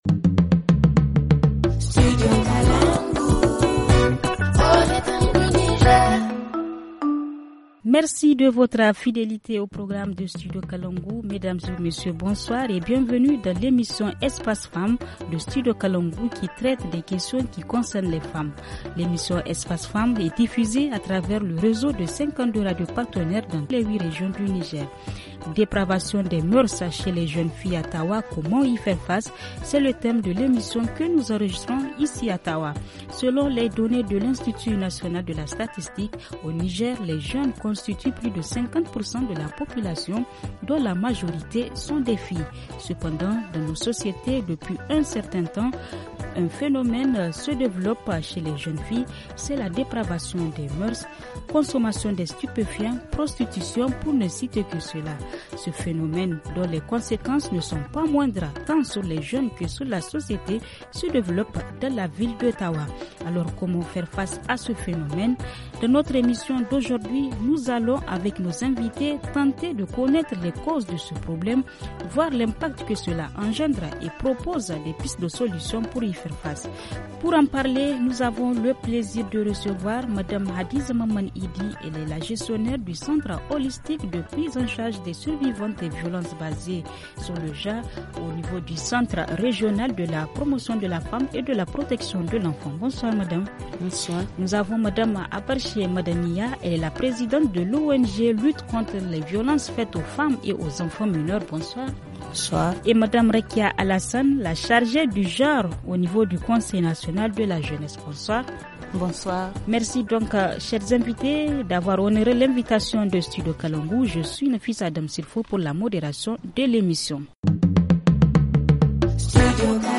Dans ce forum nous allons, avec nos invités, tenter de connaître les causes de ce problème et de l’impact que cela engendre pour la société. Nous allons également, faire le point des propositions de pistes de solution.